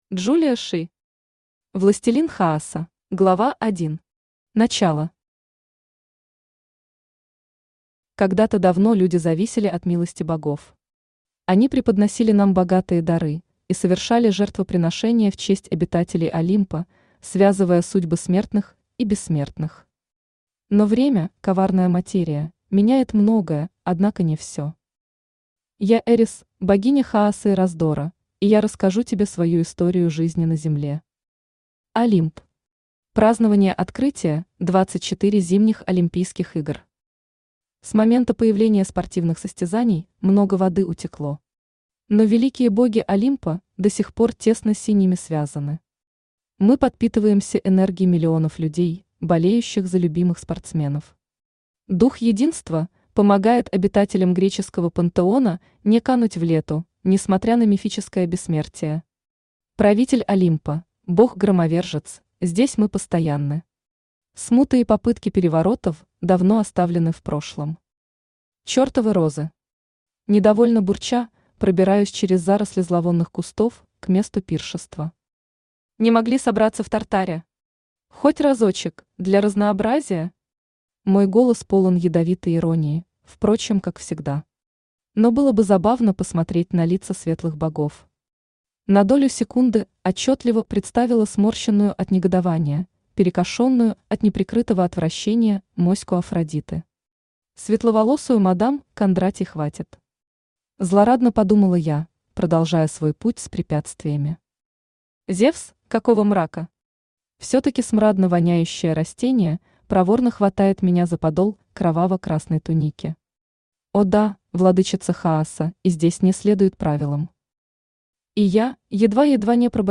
Aудиокнига Властелин Хаоса Автор Julia Shi Читает аудиокнигу Авточтец ЛитРес.